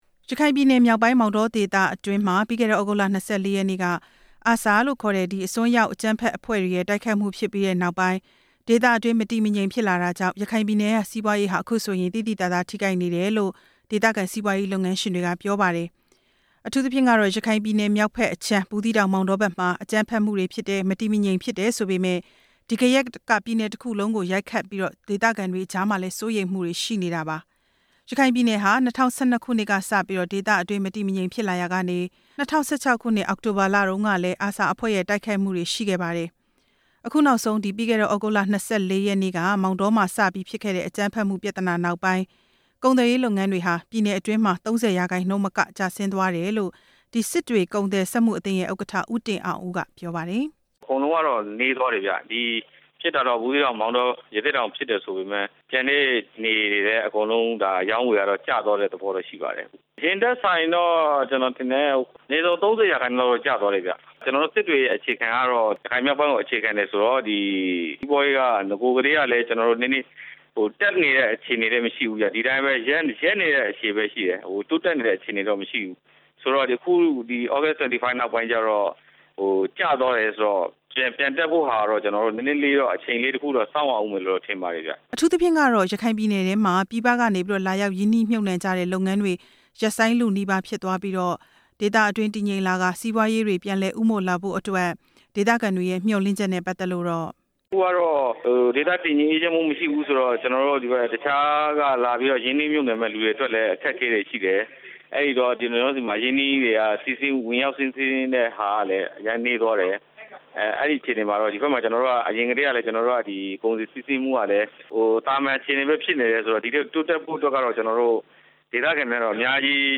ရခိုင်ပြည်နယ် စီးပွားရေး လုပ်ငန်းရှင်တချို့ ပြောပြချက်